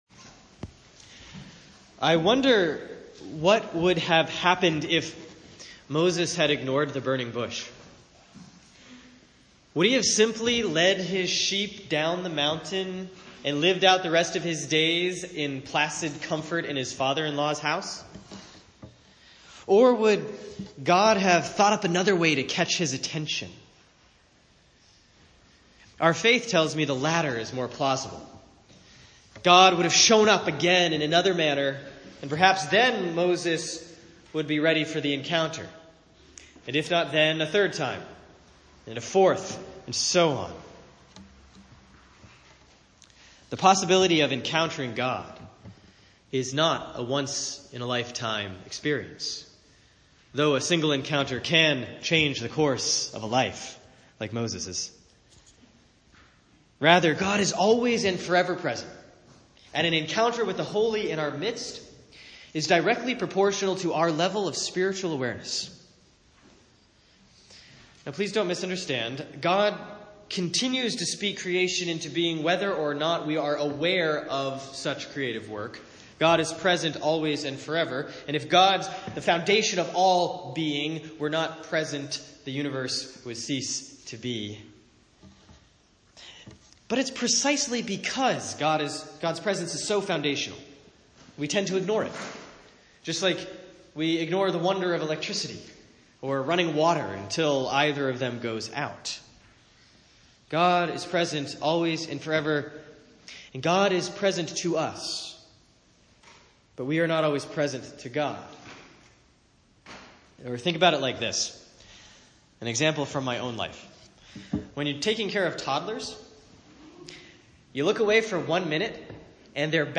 Sermon for Sunday, September 3, 2017 || Proper 17A || Exodus 3:1-15